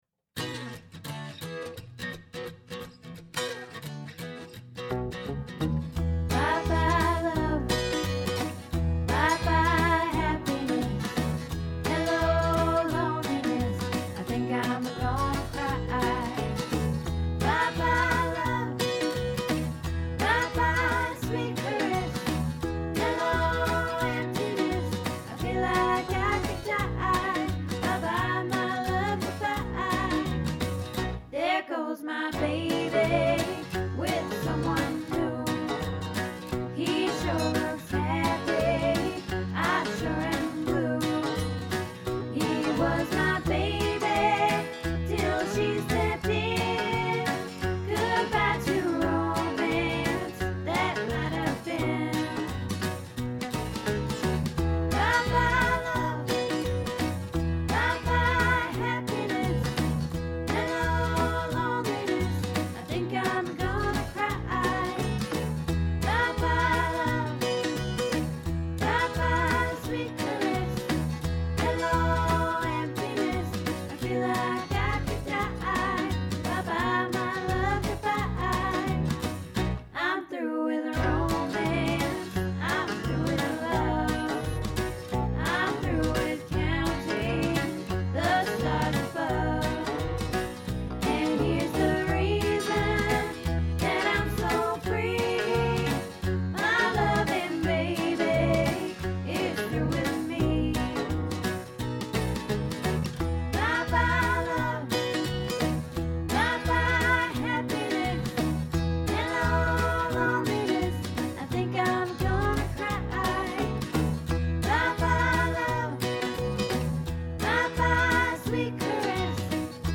Bye Bye Love Soprano